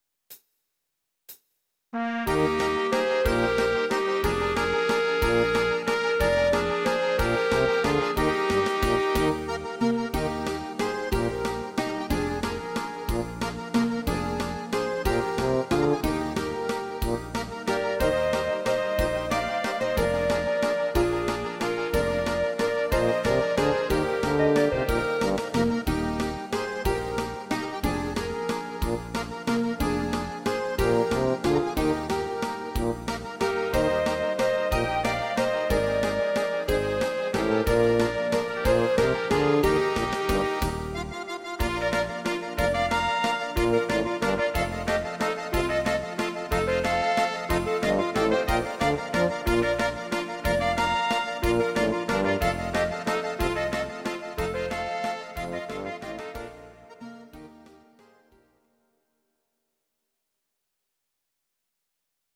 These are MP3 versions of our MIDI file catalogue.
Please note: no vocals and no karaoke included.
Your-Mix: Traditional/Folk (1155)